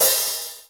METL.HAT.wav